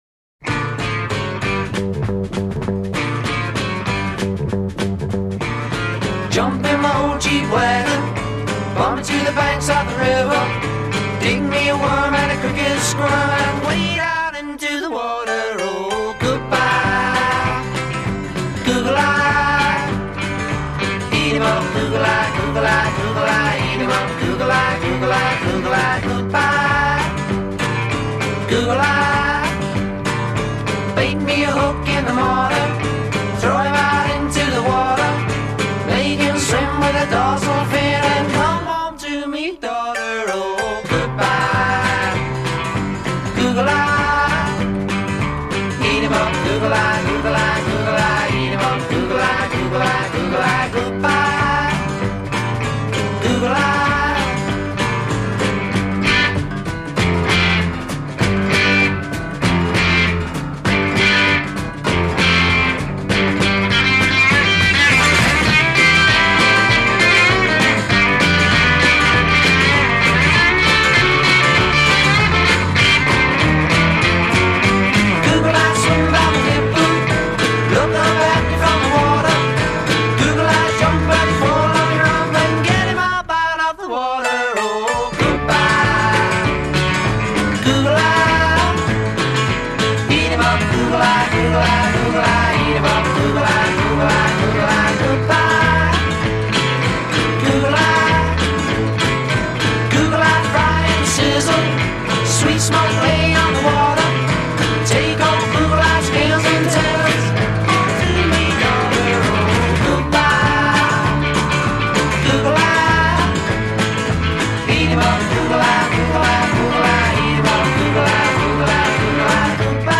piano
bass
vocals
guitar
drums
Intro 0:00 4 Guitar-piano opposite movement theme
(bass descends opposite guitar): G maj.
A Verse : 8 Unison vocals. a
B Refrain : 12 2-part harmonies alternate with unisons. b
A Verse : 8 Guitar solo
B Refrain : 8 Ensemble (modulate to A major)